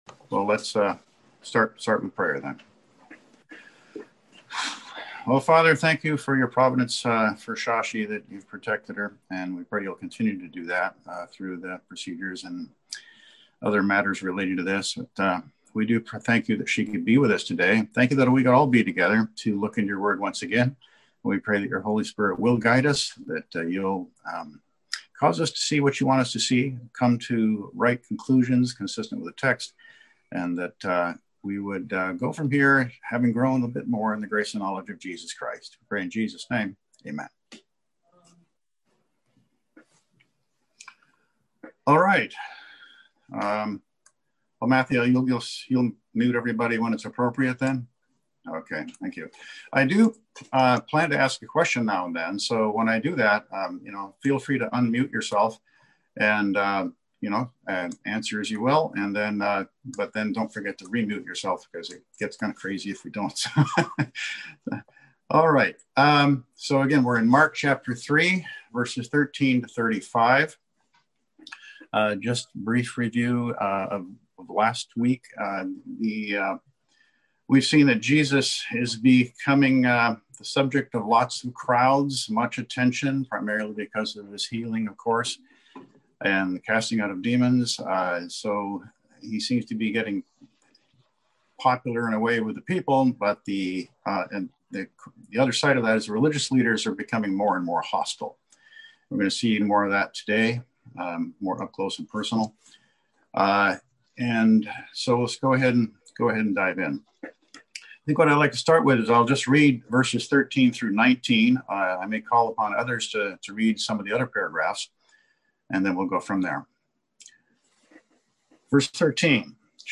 Passage: Mark 3:13-35 Service Type: Sunday School